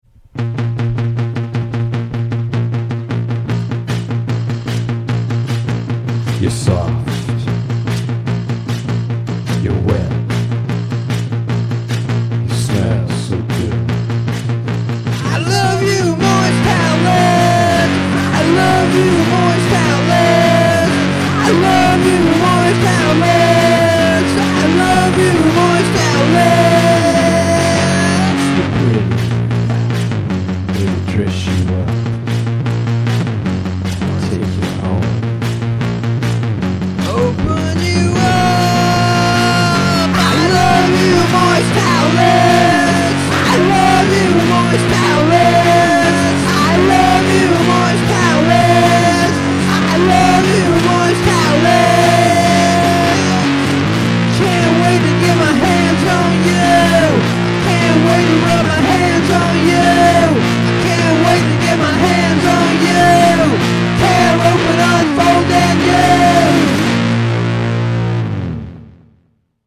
From the "artist":  I swear my voice isn't that bad!
Also my apologies to drummers (like myself) for using electronic drums...I did actually play them (as opposed to a "drum machine"), and had to resort to electronics as I could not find the energy and instrumentation to mic my regular drum set.